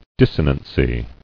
[dis·so·nan·cy]